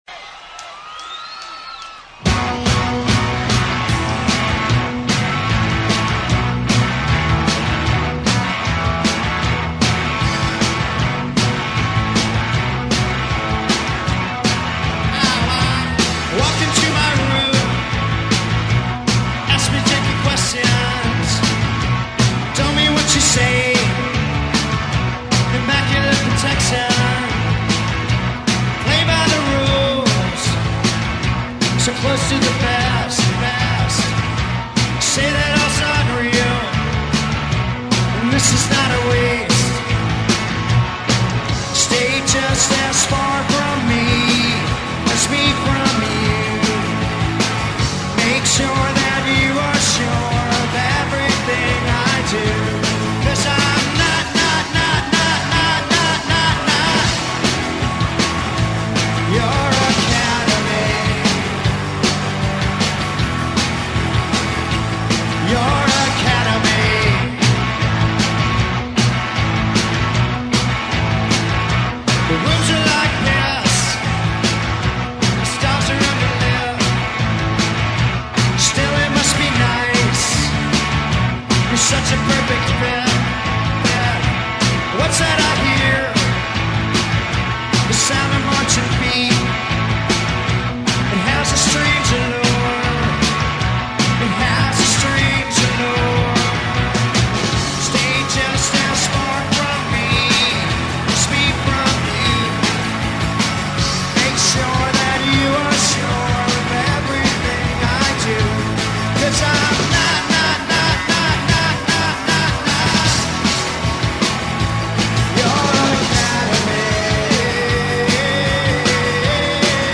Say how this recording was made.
bootleg